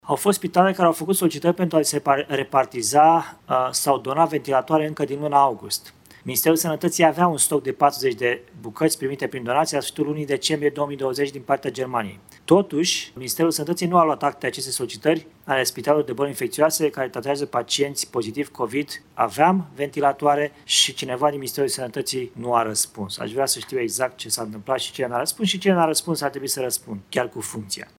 Într-o o videoconferință cu reprezentanții Centrului Național de Conducere și Coordonare a Intervenției de la Ciolpani,  acesta a arătat din nou spre Ministerul Sănătății care ar fi tergiversat achiziția unui medicament care se folosește în tratarea cazurilor de coronavirus.
08oct-15-Citu-despre-ventilatoarele-cerute-si-nealocate.mp3